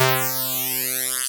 ihob/Assets/Extensions/RetroGamesSoundFX/Electricity/Electricity2.wav at master
Electricity2.wav